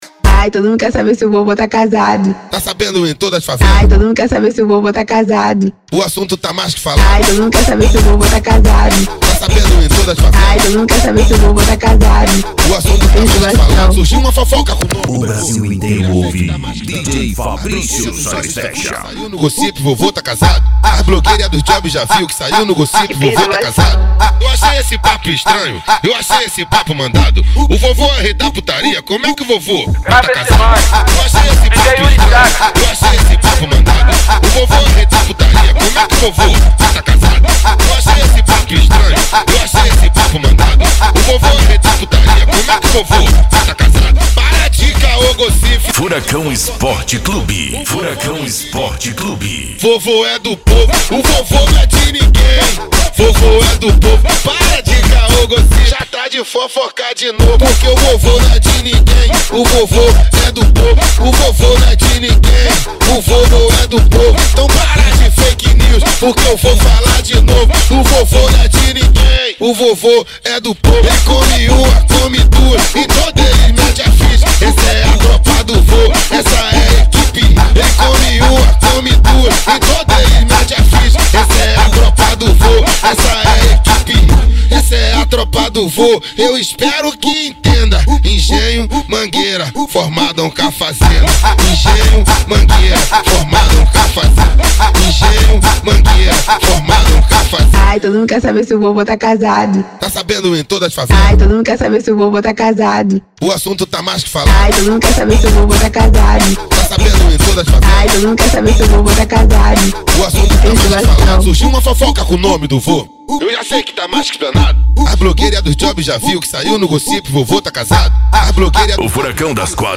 Funk
SERTANEJO
Sertanejo Raiz